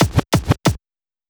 FK092BEAT5-L.wav